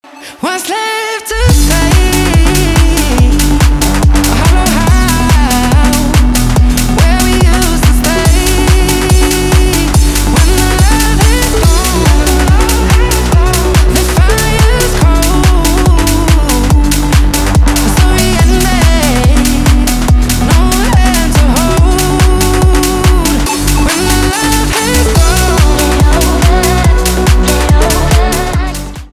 танцевальные
битовые , басы , качающие , кайфовые , грустные